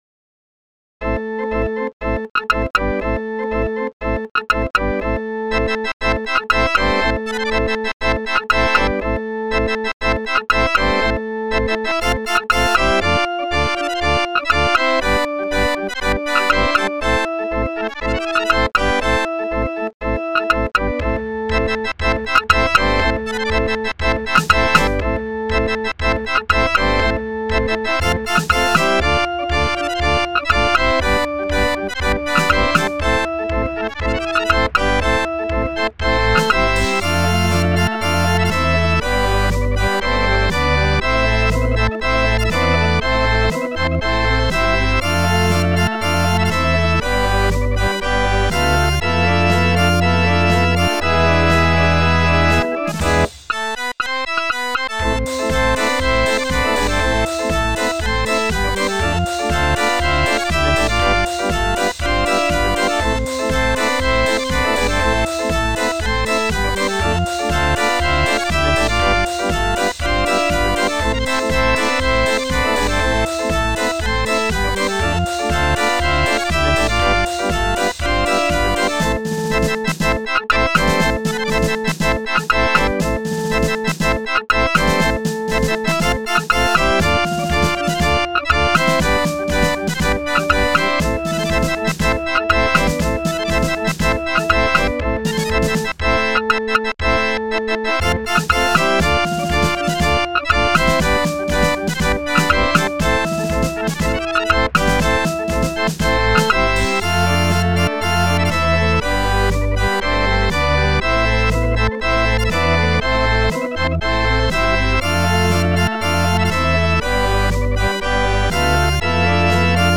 Musikrollen, Notenbücher und Zubehör für Drehorgeln.